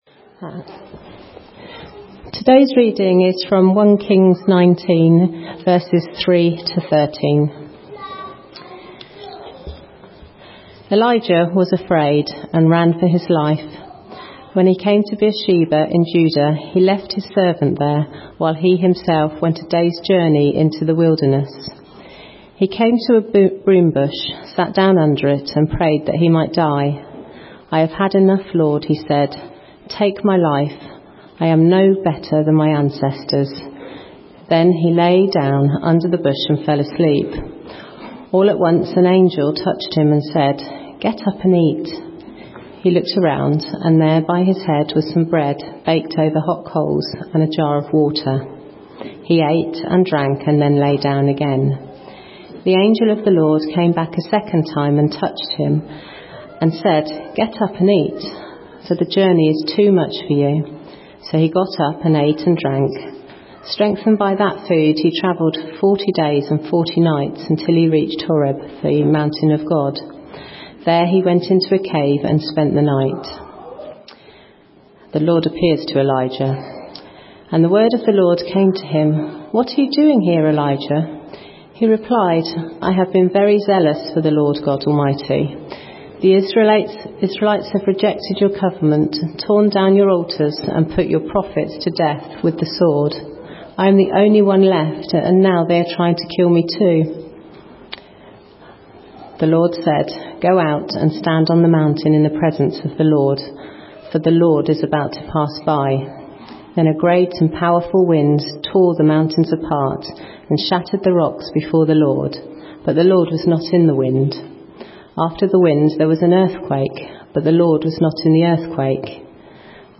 A message from the series "Wellbeing Journey."
From Series: "Sunday Morning - 10:30"